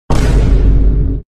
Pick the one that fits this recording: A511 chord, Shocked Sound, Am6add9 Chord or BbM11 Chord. Shocked Sound